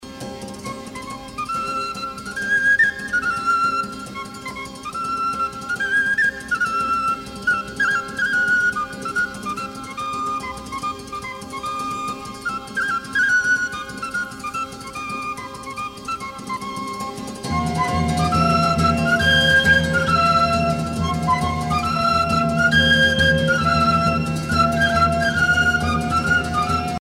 Estampie et ungaresca
danse : estampie (moyen âge)
Pièce musicale éditée